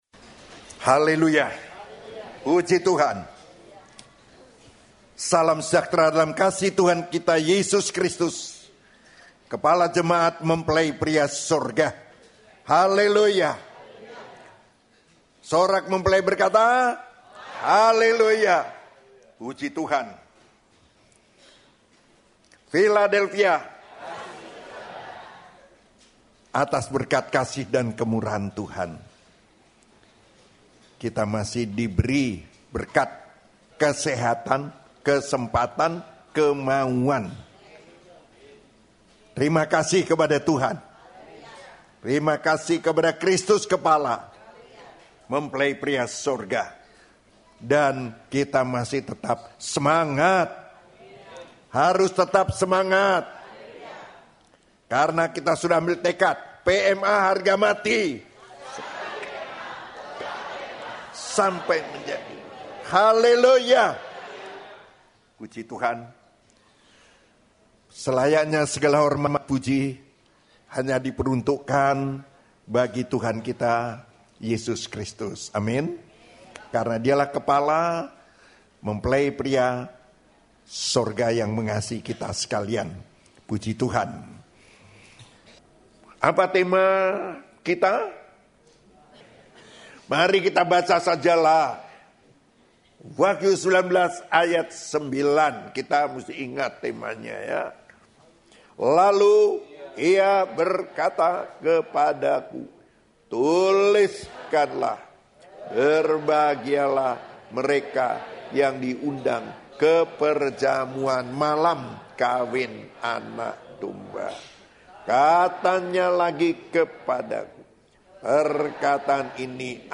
Khotbah (Audio)
Khotbah Pengajaran